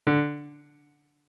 MIDI-Synthesizer/Project/Piano/30.ogg at 51c16a17ac42a0203ee77c8c68e83996ce3f6132